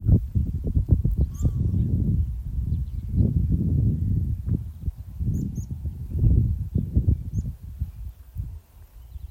Pļavu čipste, Anthus pratensis